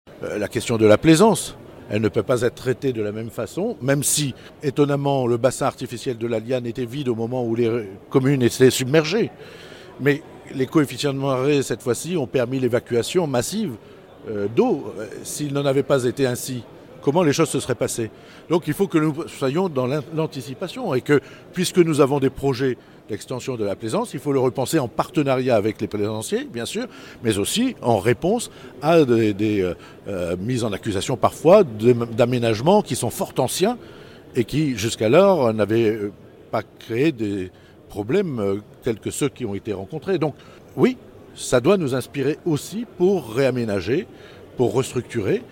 Durant les vœux aux élus du Boulonnais, le président Frédéric Cuvillier est revenu un long moment sur les conséquences des inondations. Il a posé la question de la présence des bateaux des plaisanciers à l'Ecluse Marguet.